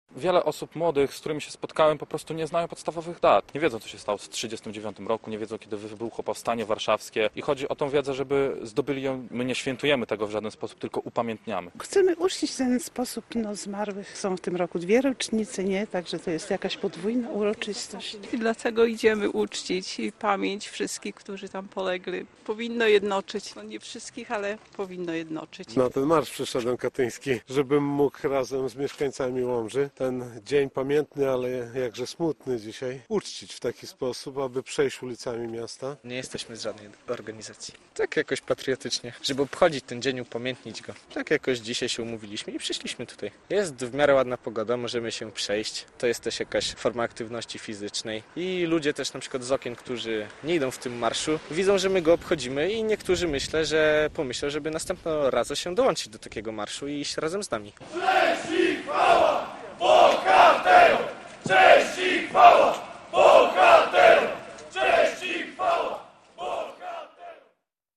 I Marsz Katyński w Łomży - relacja